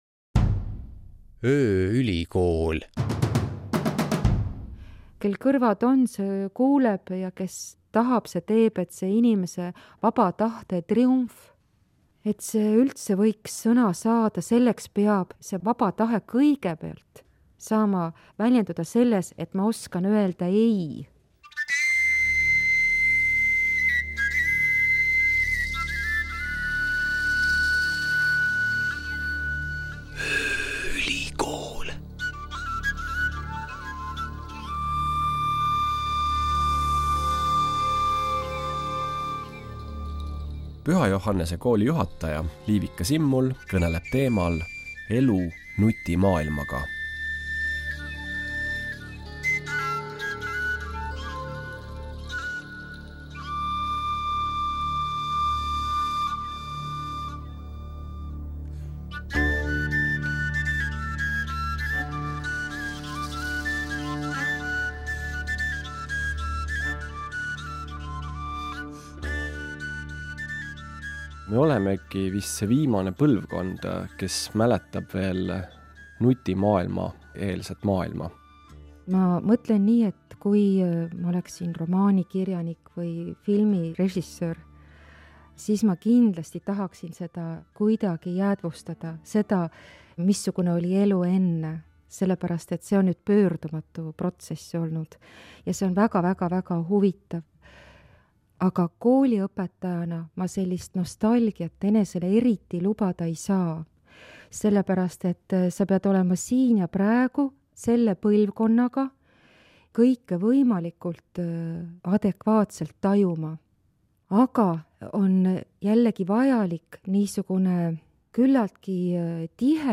Vestlusring